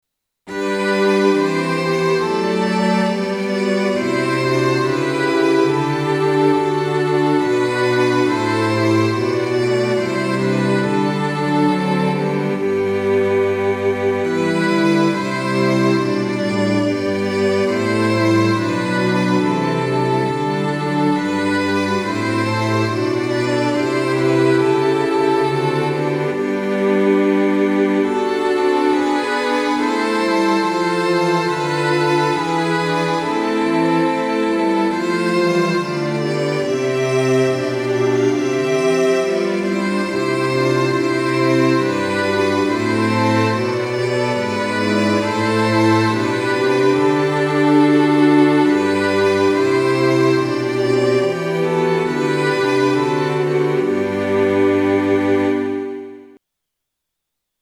Sample Sound ：MIDI⇒MP3
Tonality：G (♯)　Tempo：Quarter note = 70